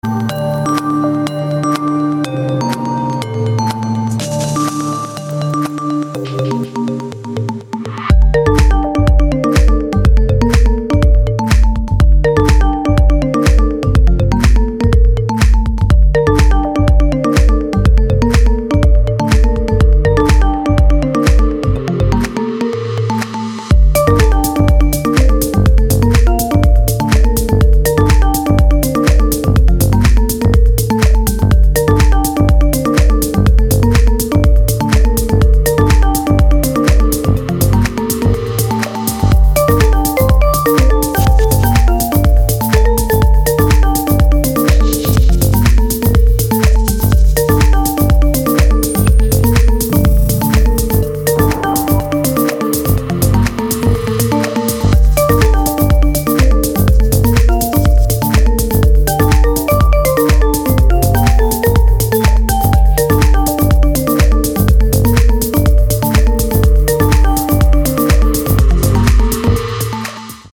• Качество: 224, Stereo
без слов
ксилофон
Жанр: Techno, Electronica